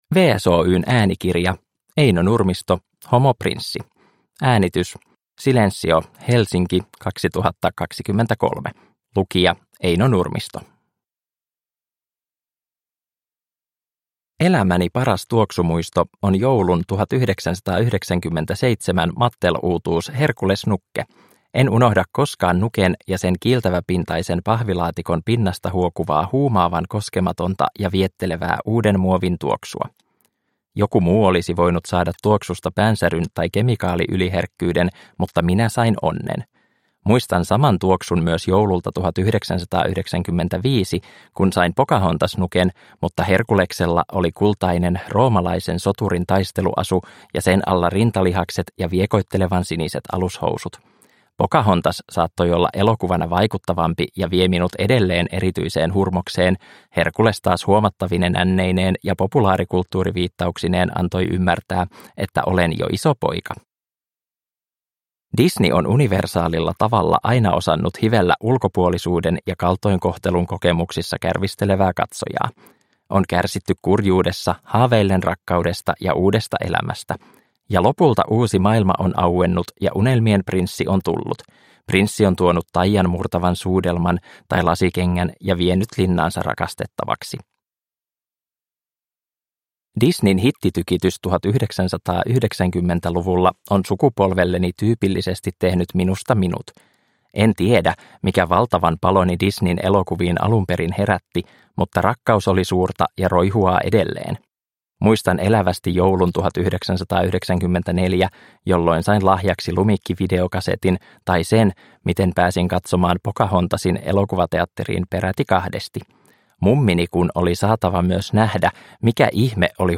True love: Homoprinssi – Ljudbok – Laddas ner